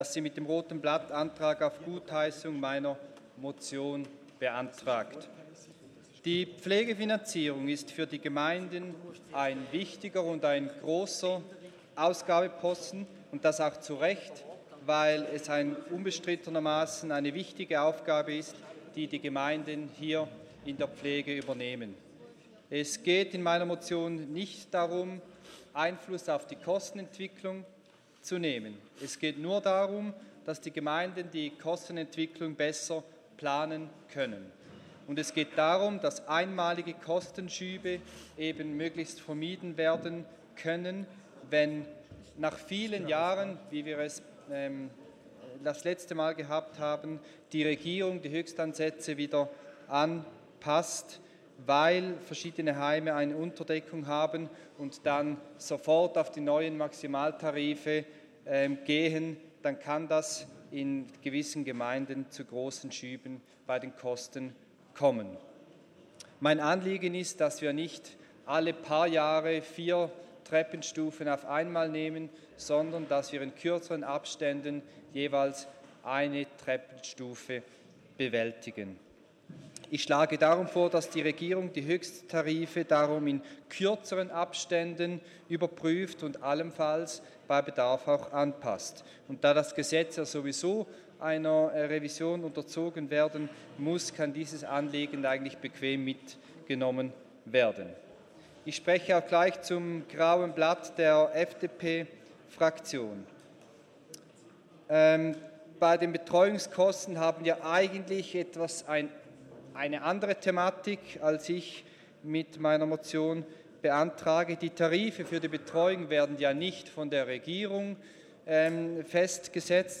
Session des Kantonsrates vom 23. und 24. April 2019